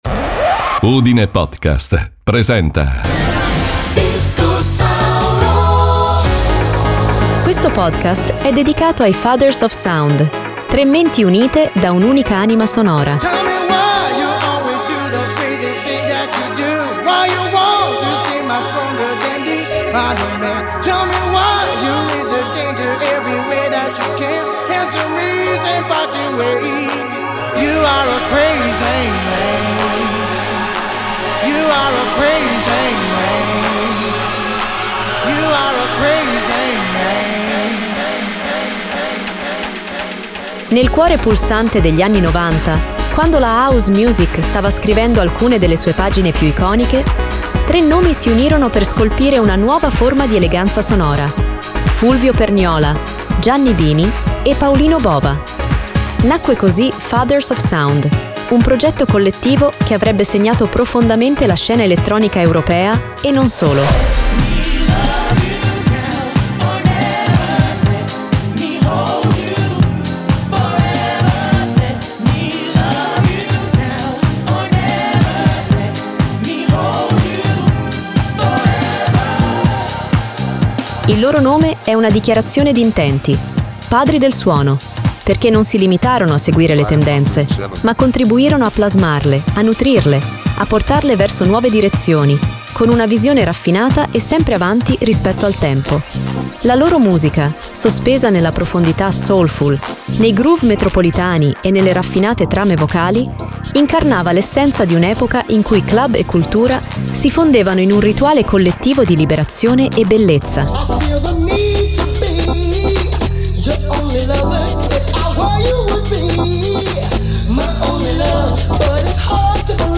Ogni traccia è costruita con un rispetto quasi artigianale per il suono: kick rotondi, bassline avvolgenti, voci che non accompagnano, ma raccontano, e un’estetica che eleva il club a luogo dell’anima.